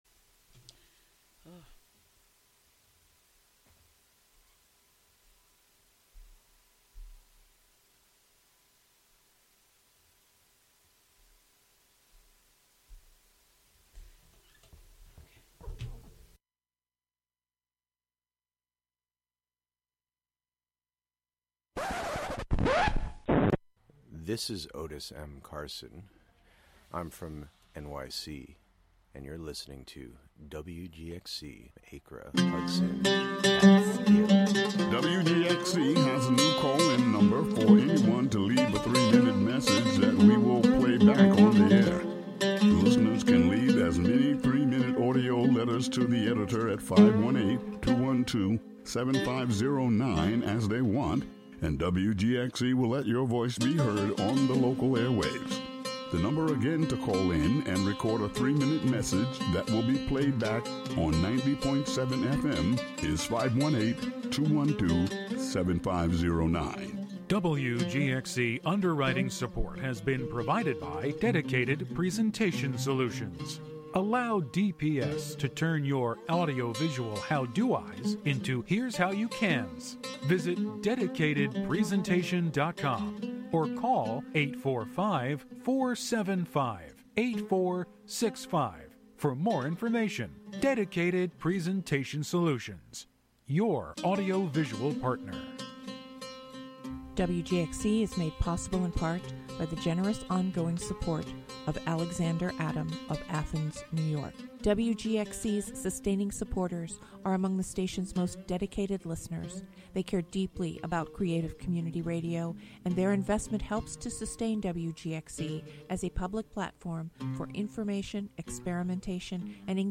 On this monthly program, “La Ville Inhumaine” (The Inhuman City), you will hear music, found sounds, words, intentional noise, field recordings: altogether, all at once.